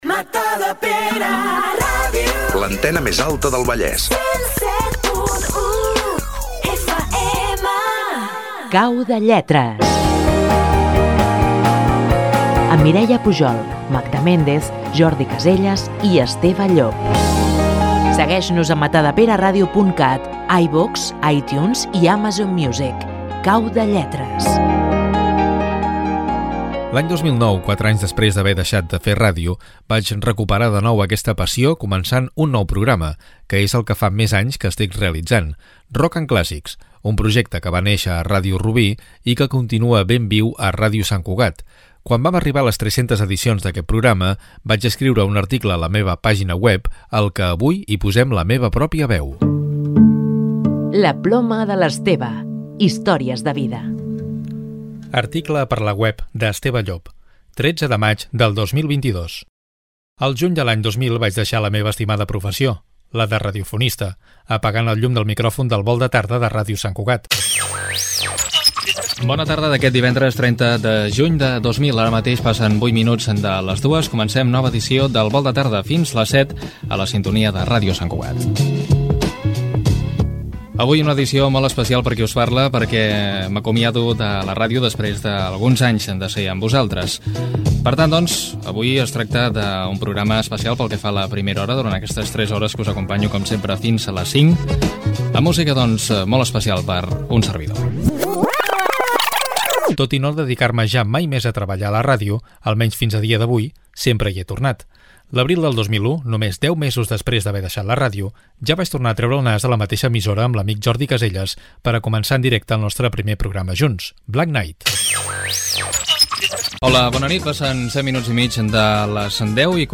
Crònica de dues dècades de programes de ràdio amb talls de diferents programes.